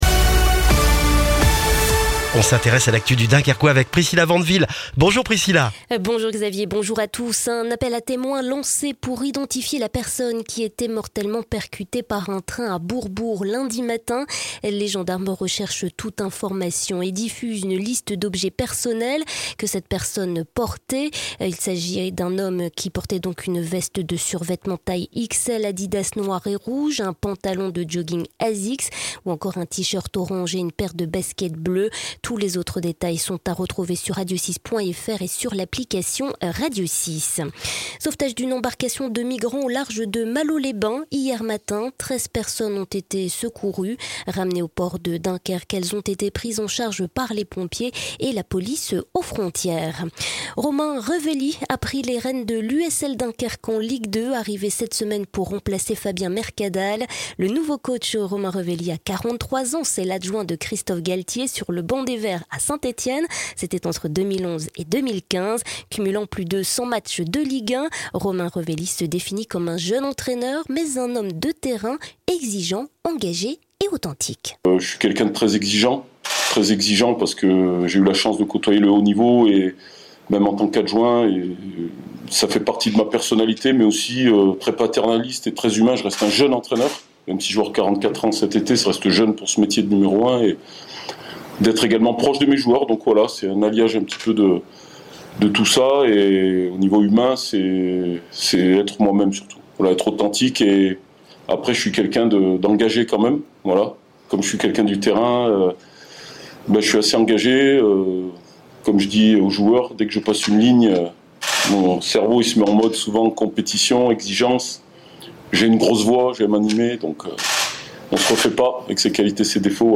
Le journal du mercredi 16 juin dans le Dunkerquois